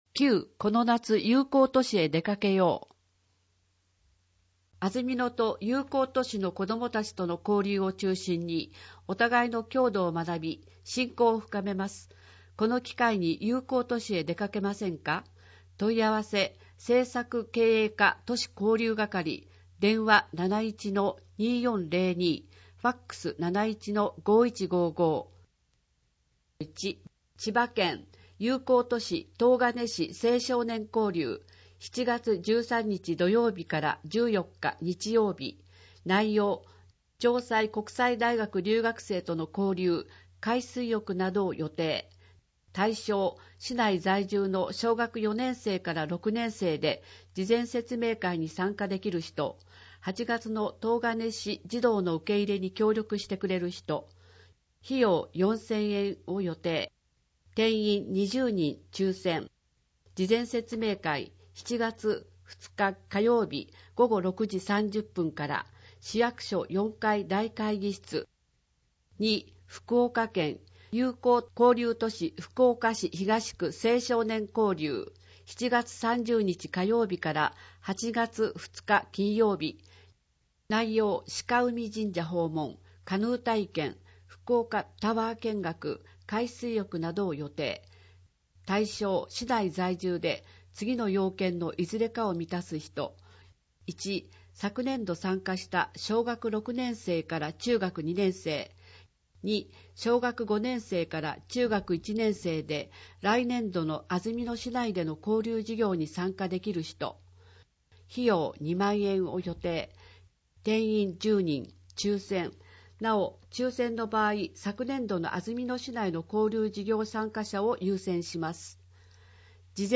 広報あづみの朗読版291号（令和元年5月22日発行)
「広報あづみの」を音声でご利用いただけます。